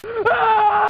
Ooff3.wav